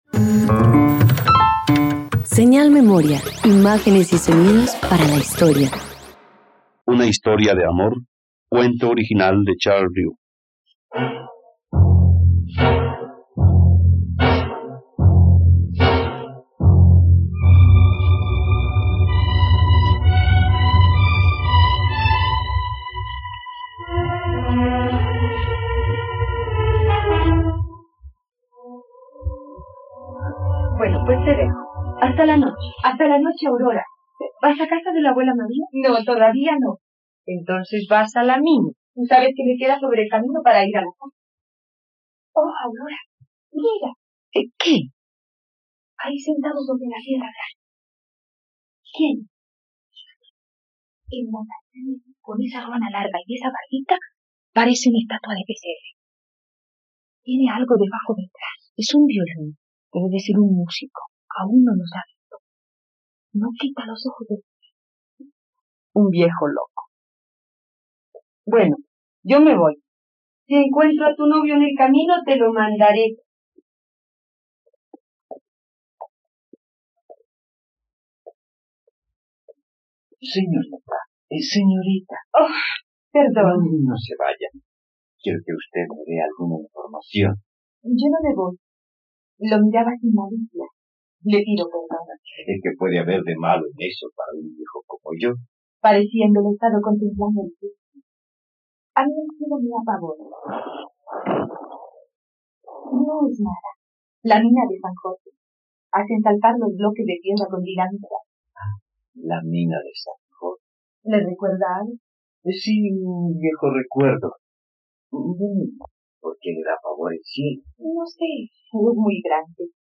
..Radioteatro. Escucha la adaptación para radio de la obra "Una historia de amor" de Charles Riu en la plataforma de streaming RTVCPlay.